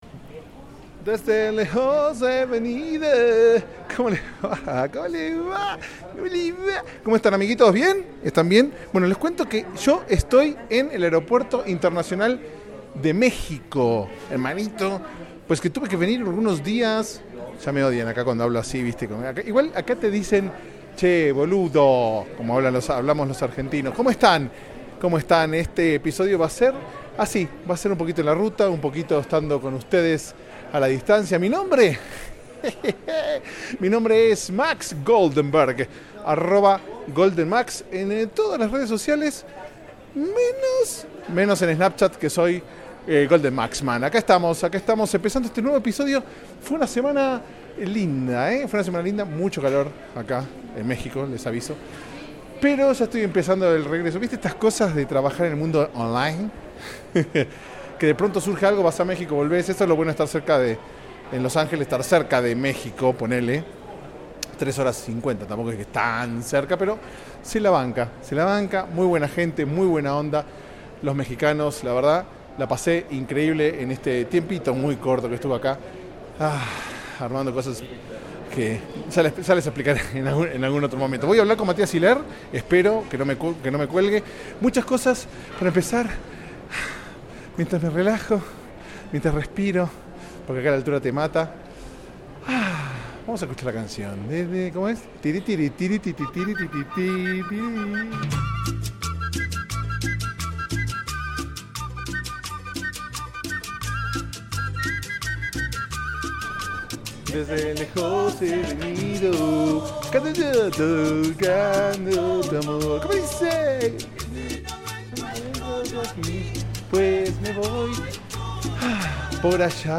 Grabado en la ruta (real) entre México y Los Angeles, intentando no perder el aliento a medida que corría por los aeropuertos.
En realidad no charlé con nadie sino que divagué mientras me agitaba como un asno.